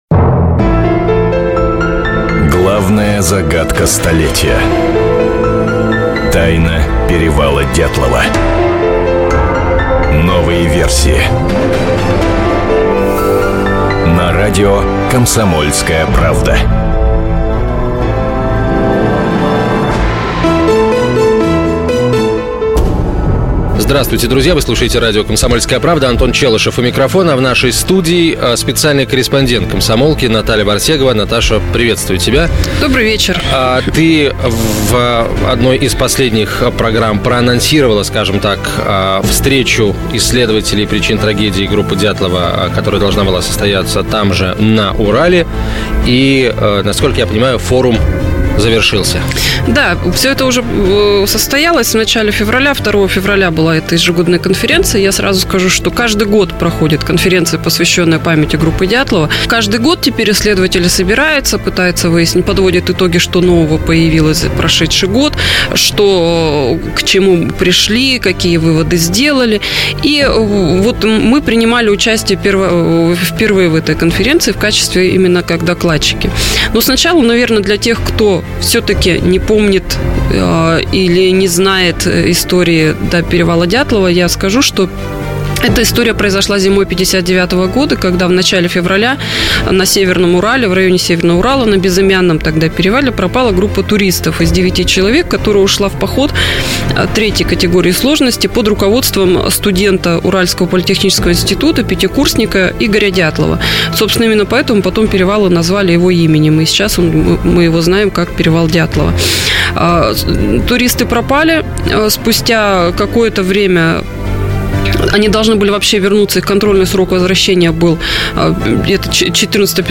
Аудиокнига Съезд исследователей причин трагедии | Библиотека аудиокниг